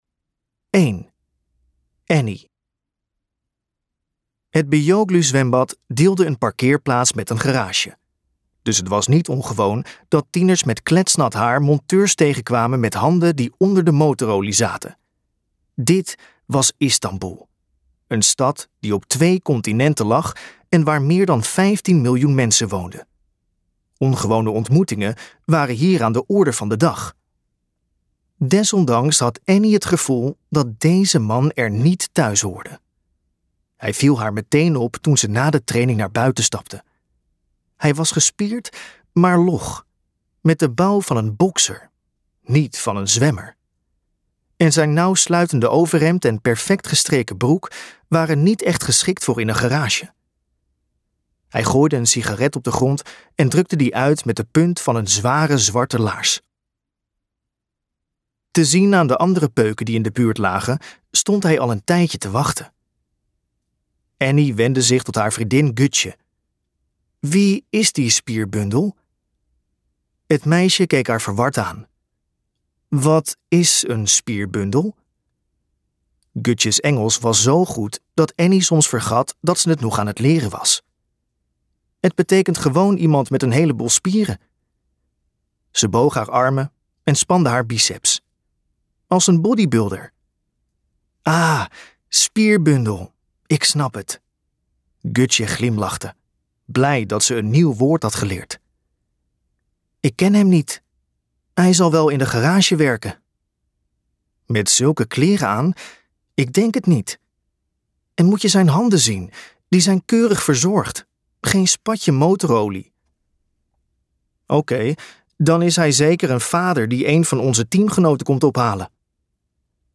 Uitgeverij De Fontein | Race tegen de klok luisterboek